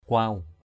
/kʊaʊ/ (d.) gỗ gáo giấy.